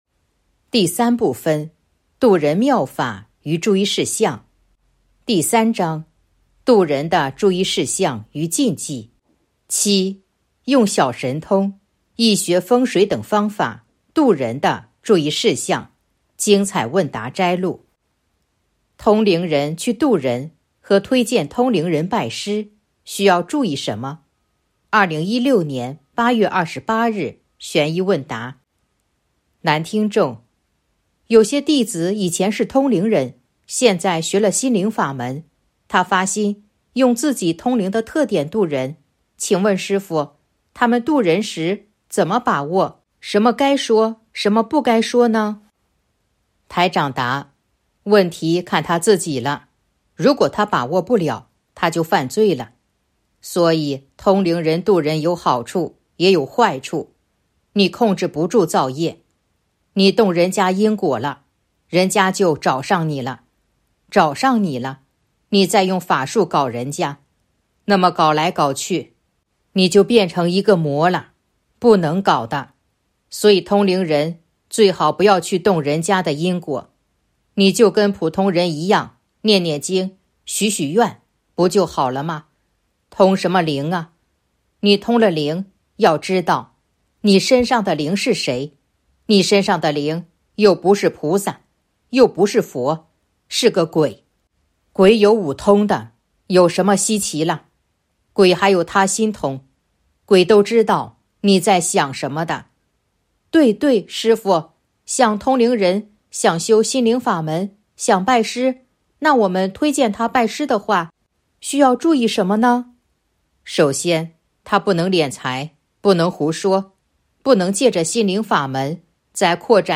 061.精彩问答摘录《弘法度人手册》【有声书】